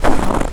HauntedBloodlines/STEPS Snow, Walk 09-dithered.wav at main
STEPS Snow, Walk 09-dithered.wav